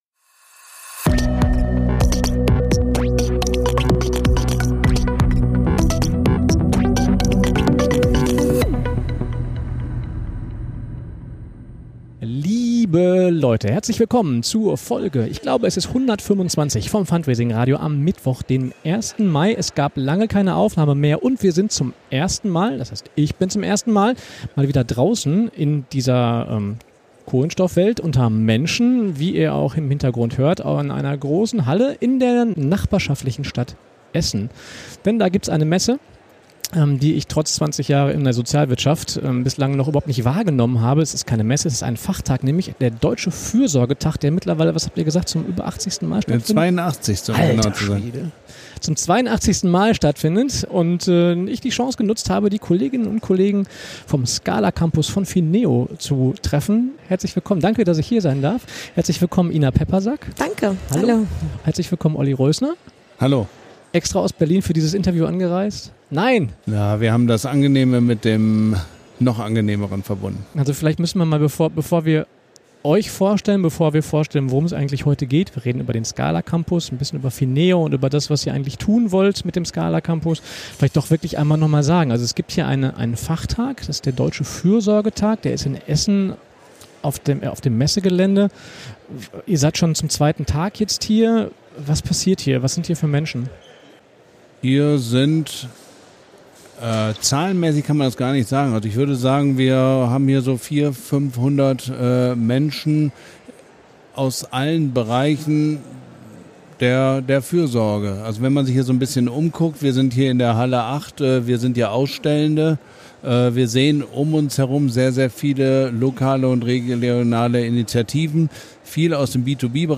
Entschuldigt bitte die etwas eingeschränkte Audioqualität. Wir standen mitten in der Messehalle.